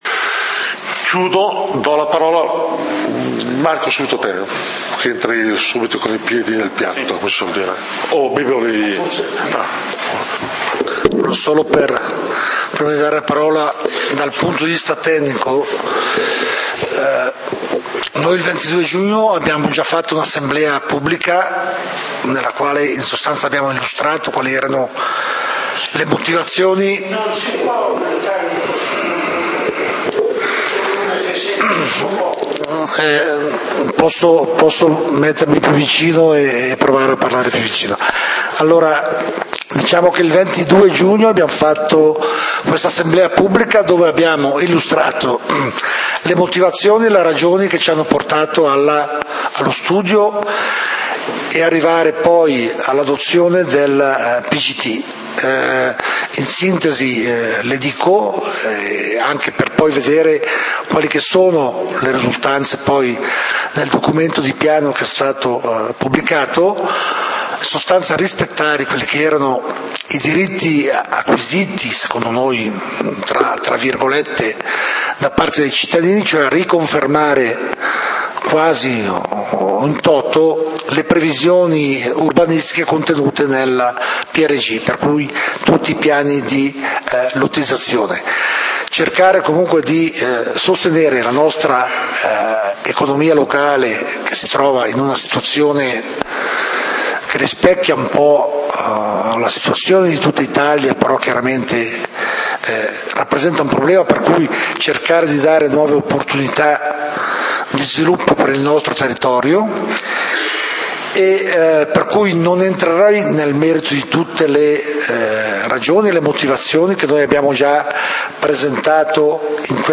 Assemblea pubblica del comunale di Valdidentro del 04 Ottobre 2013
Assemblea pubblica sul PGT del 04 Ottobre 2013 torna alla lista dei punti Punto 2